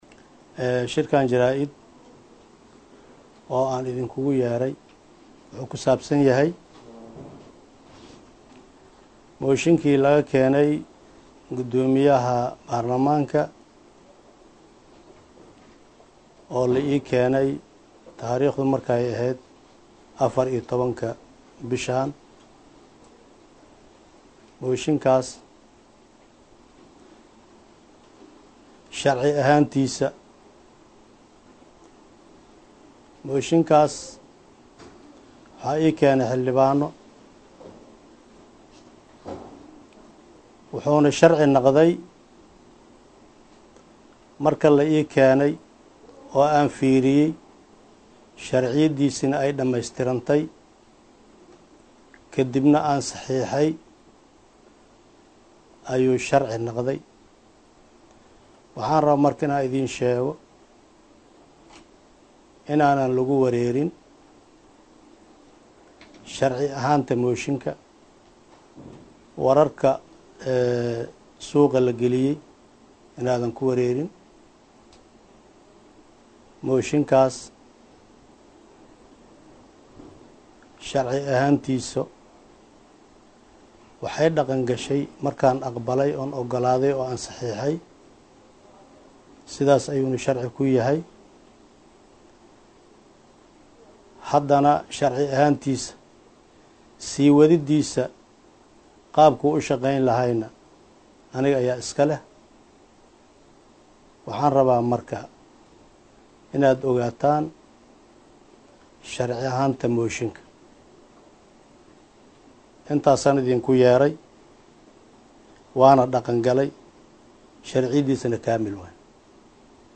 Shir Jaraa’id uu Muqdisho Caawa Warbaahinta ugu qabtay ayaa waxa uu sheegay in Gudiga joogtada ee Golaha Shacabka uu isaga kaliya u yeero karo Go’aankii ay mooshinkaas ku laaleena uusan waxba ka jirin.
Hoos Ka Dhageyso Codka Gudoomiye Muudeey.